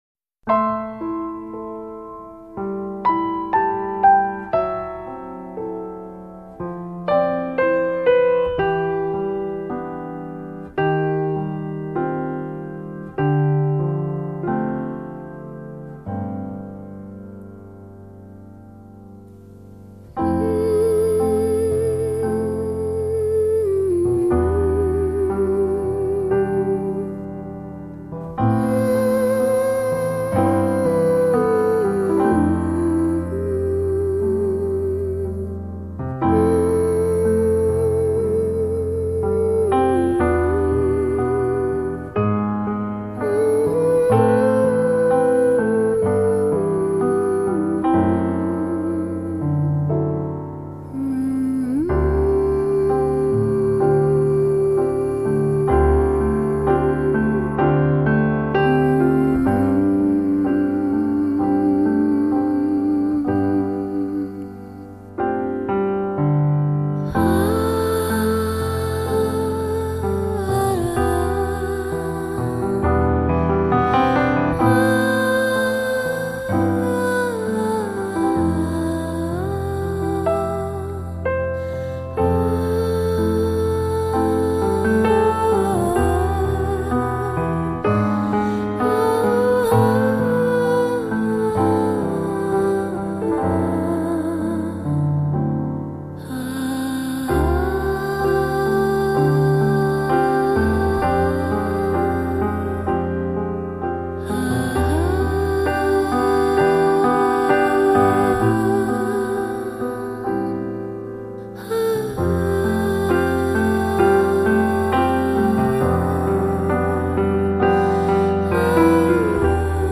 那种恬静纯然的新世纪纯音乐，
很喜欢这张精选，喜欢编者挑选曲目的精心安排，全然的舒服和放松，